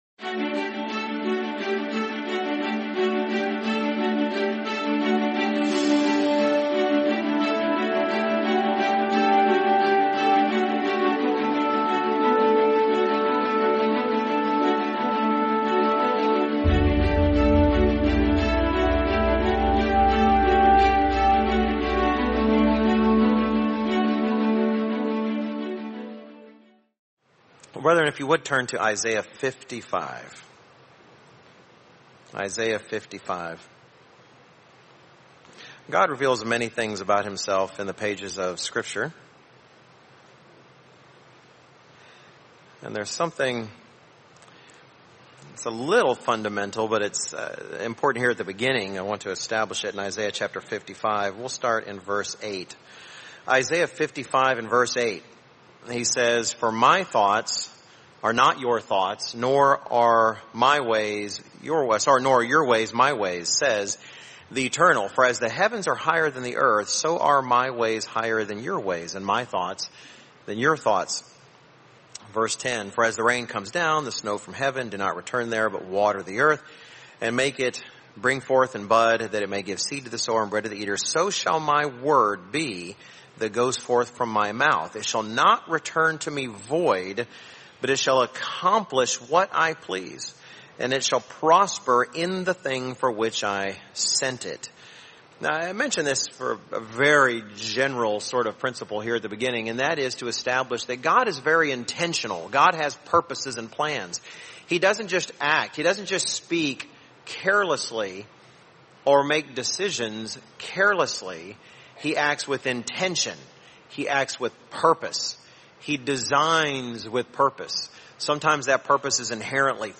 Sermon The Divine Purposes of Family